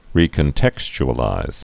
(rēkən-tĕksch-ə-līz)